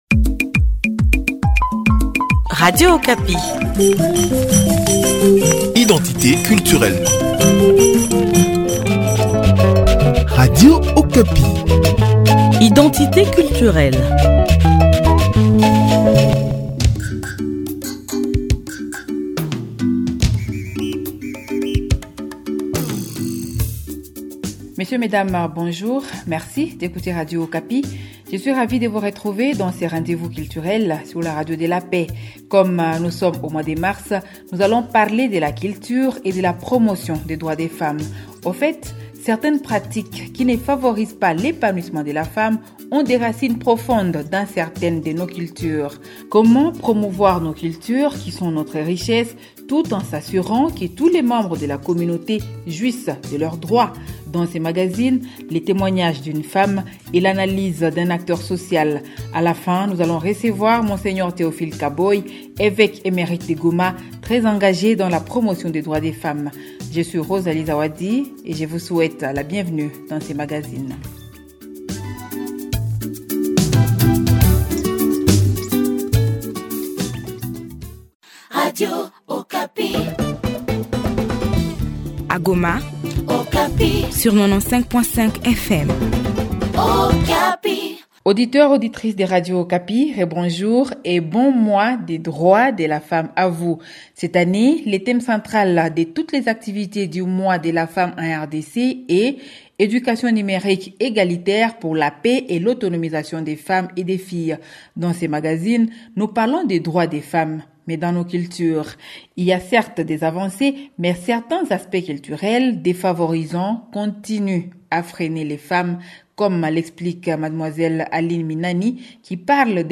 Dans ce magazine, le témoignage d’une femme et l’analyse d’un acteur social. A la fin, nous allons recevoir Mgr Théophile Kaboy, évêque émérite de Goma, très engagé dans la promotion des droits des femmes.